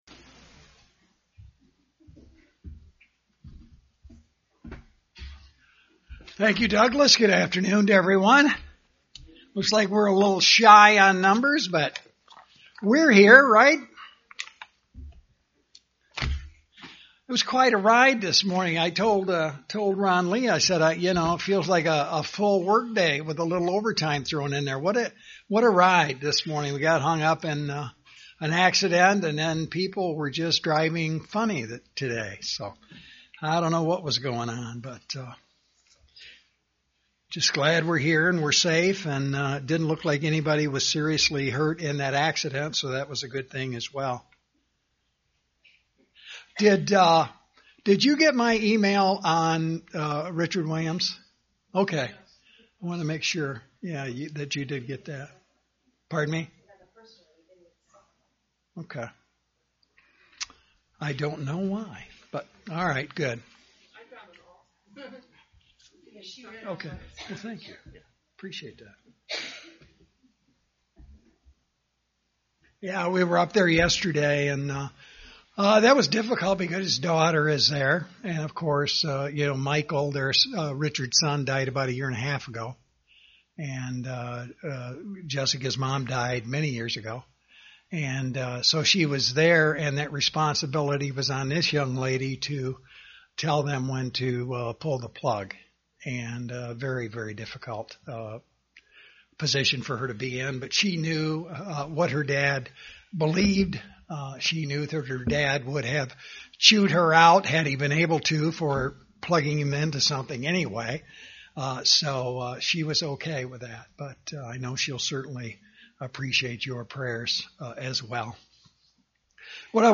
Given in Grand Rapids, MI
UCG Sermon Studying the bible?